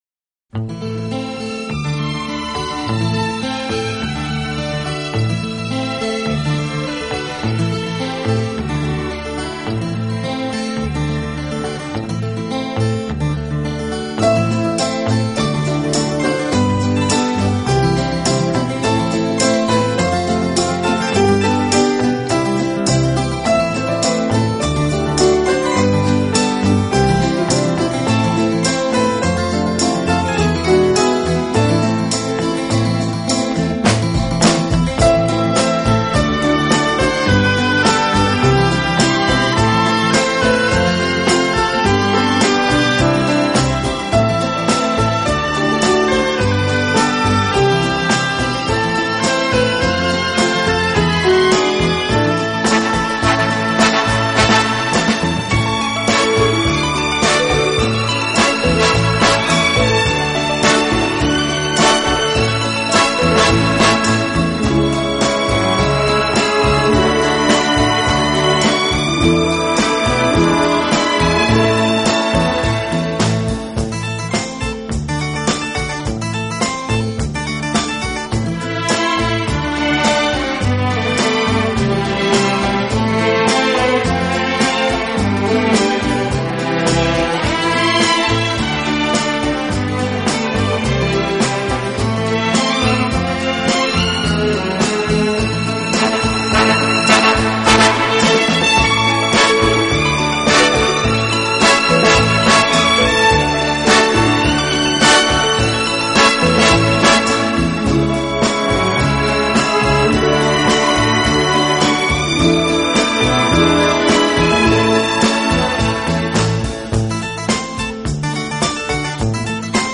音乐类型：Instrumental, Easy Listening